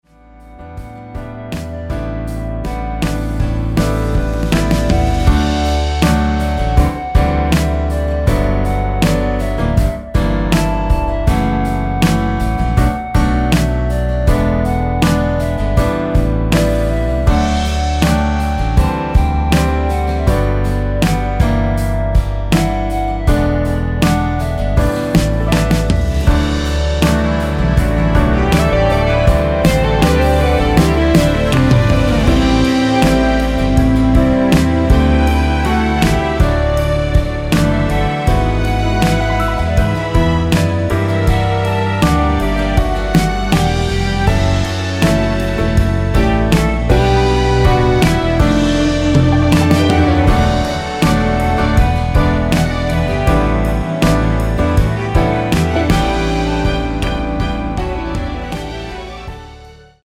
원키에서(-2)내린 멜로디 포함된 2절 삭제 MR 입니다.(미리듣기및 아래의 가사 참조)
엔딩이 페이드 아웃이라 라이브 하시기 좋게 엔딩을 만들어 놓았습니다.
노래방에서 노래를 부르실때 노래 부분에 가이드 멜로디가 따라 나와서
앞부분30초, 뒷부분30초씩 편집해서 올려 드리고 있습니다.